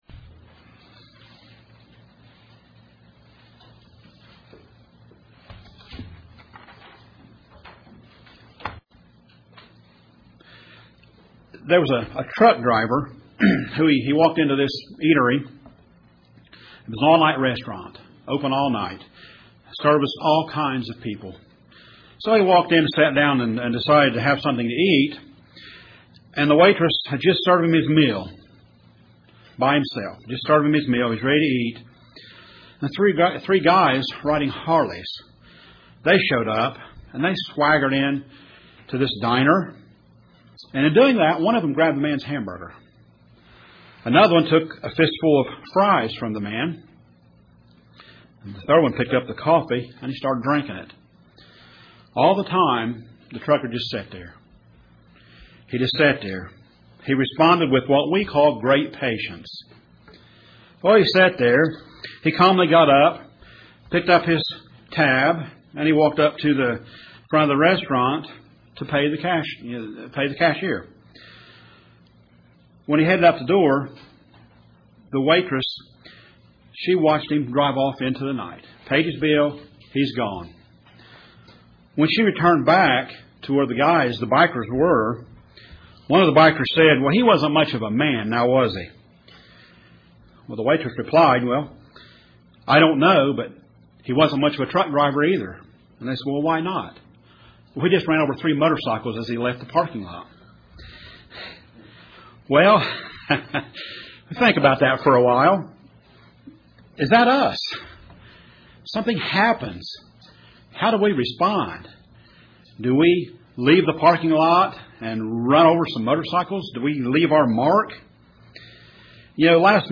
Listen as these ways are exposed in this sermon.